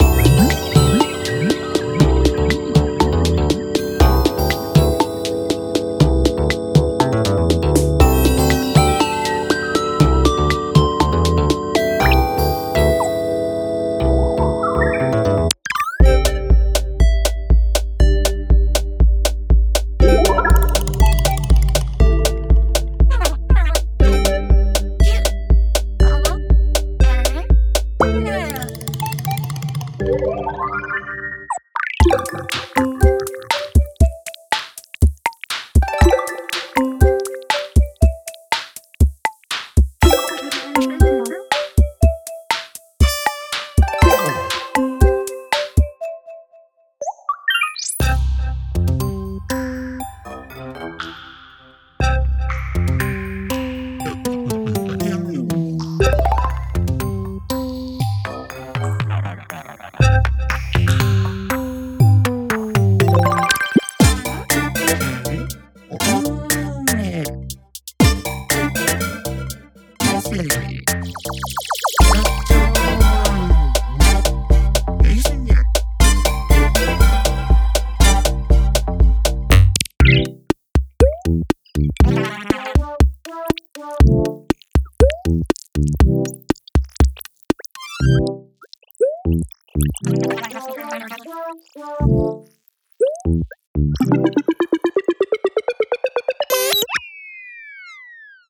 Game Audio